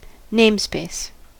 namespace: Wikimedia Commons US English Pronunciations
En-us-namespace.WAV